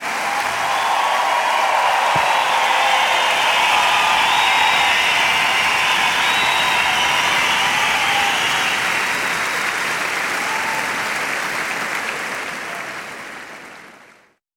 Crowd (4).wav